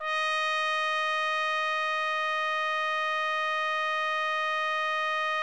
TRUMPET   22.wav